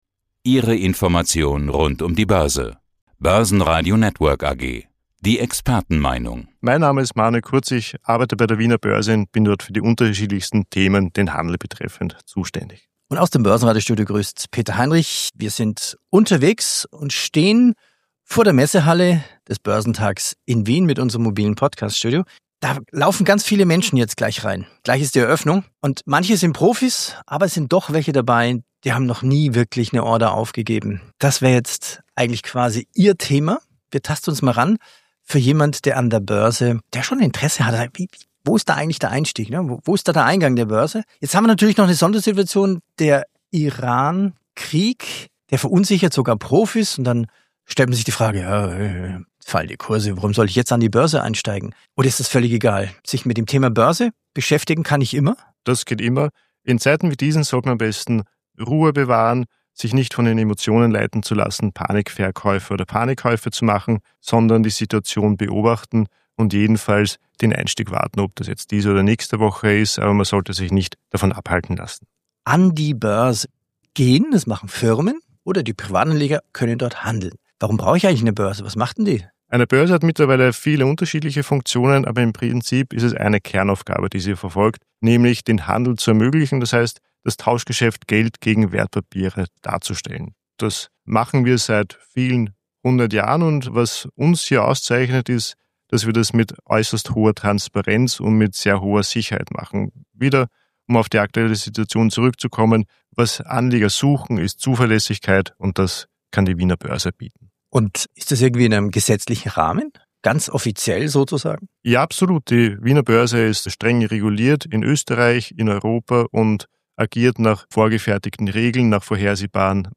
Im Interview erklärt er die Rolle von Geduld und